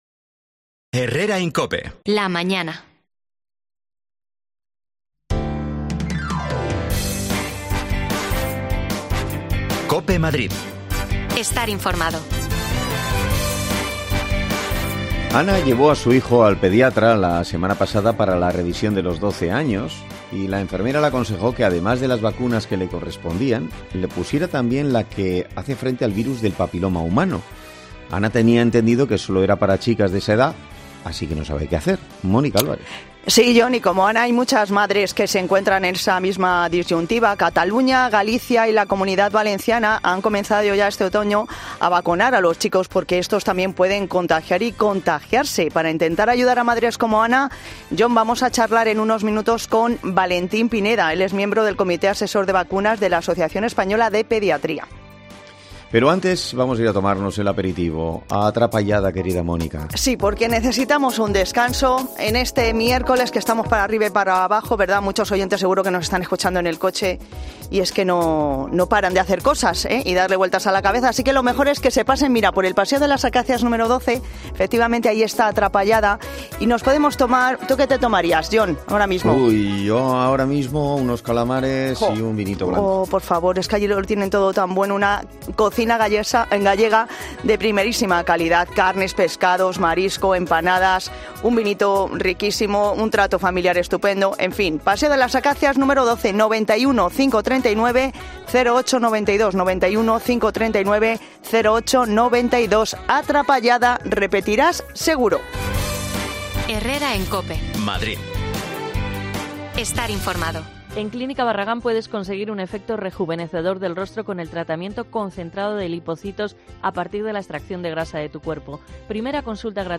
Le preguntamos a los pediatras